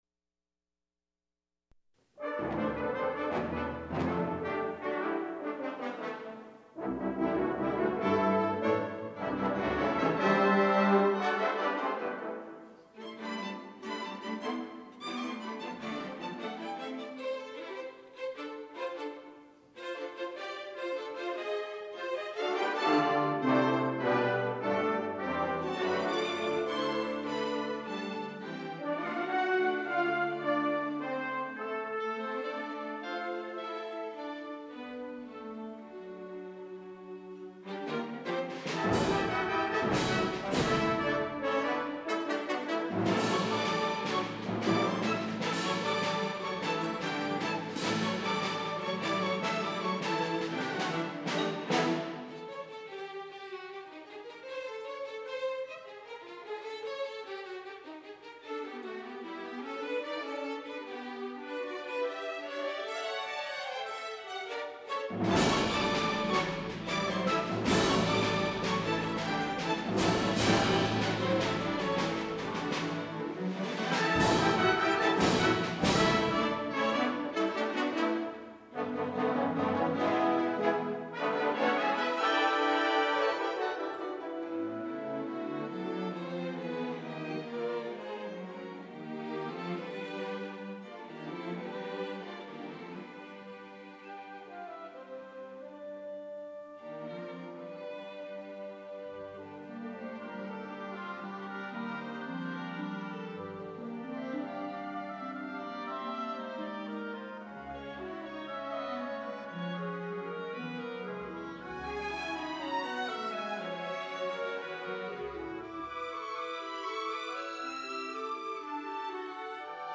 Theme: Americana
Ensemble: Full Orchestra